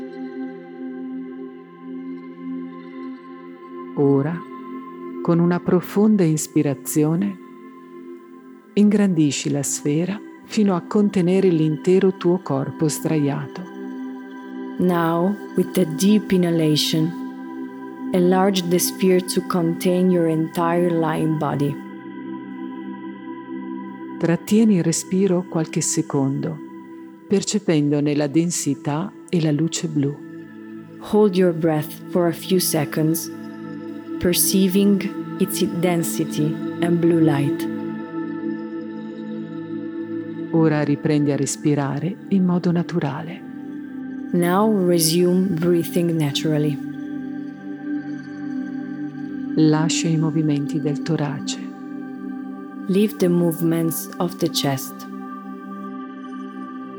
A guide to deep relaxation in the lying position, which introduces the training for the projection of consciousness beyond the physical body.
Inner-Journey-Two-voices-.m4a